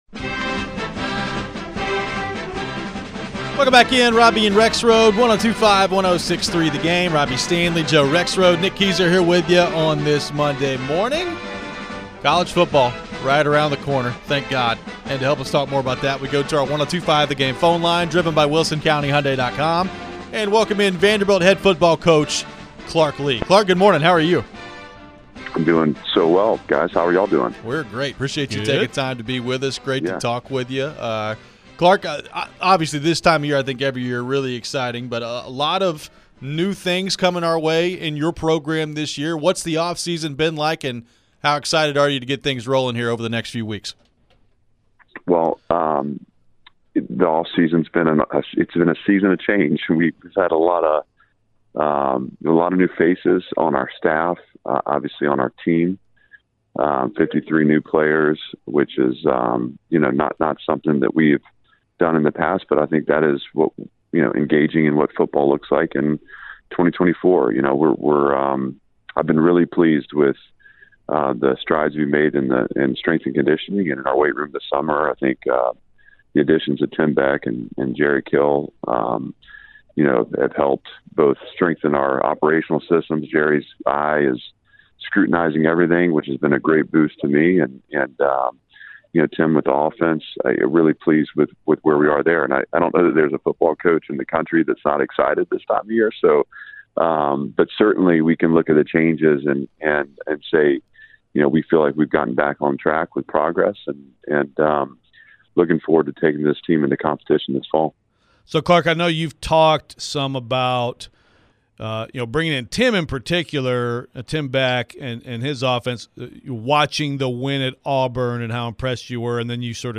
Clark Lea interview (7-15-24)
Vanderbilt football head coach Clark Lea joined the show ahead of SEC media days in Texas. How does he feel about the changes to his program? Who are some players to keep an eye on this fall if you're a Vandy fan?